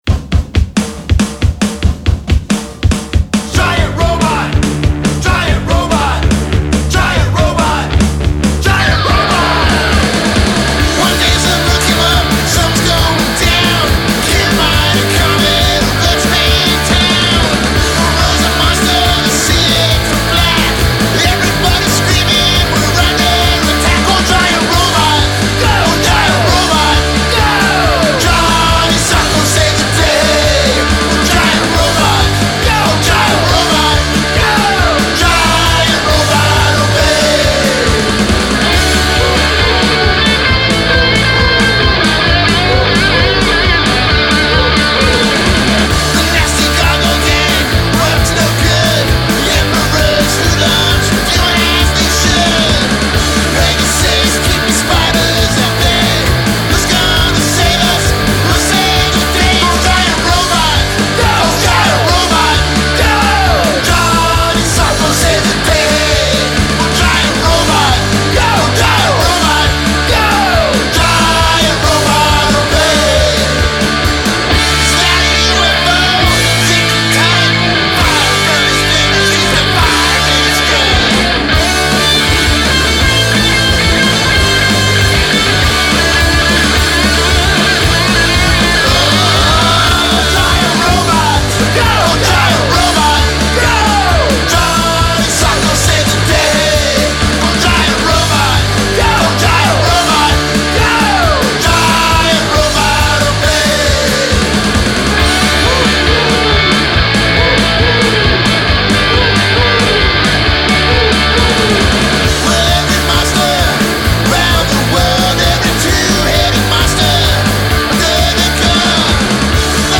Recorded at Mole Tracks West